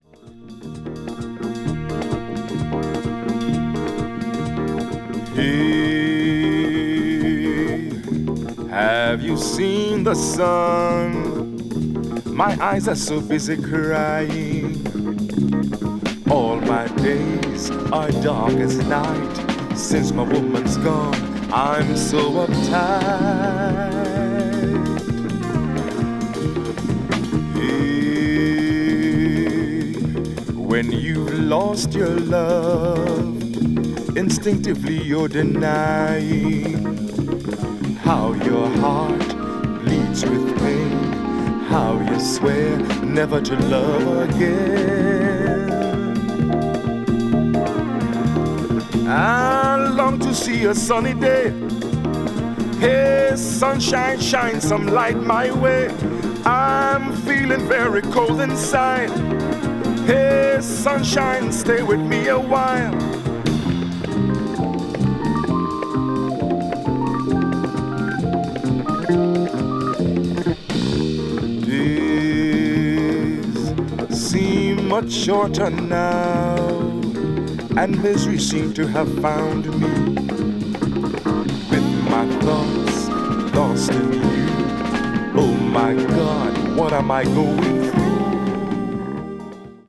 funk   jazz funk   rare groove   soul